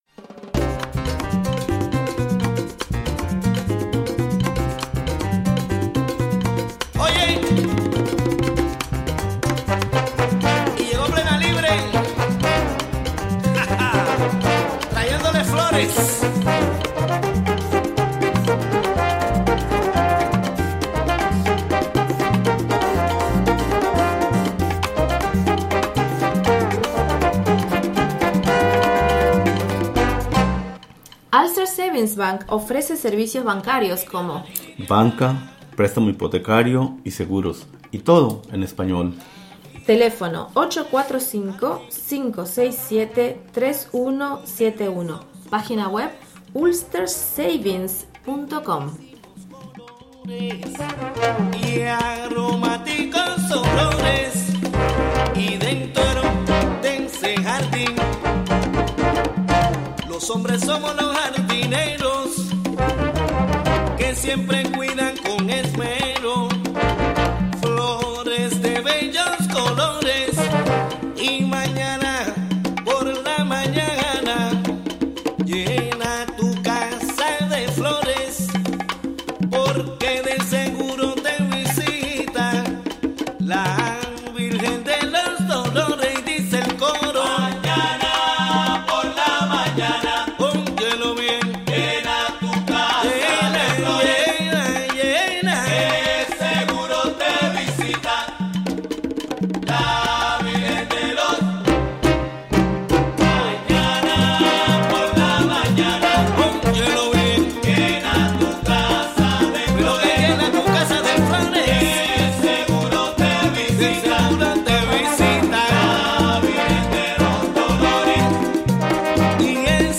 9am Un programa imperdible con noticias, entrevistas,...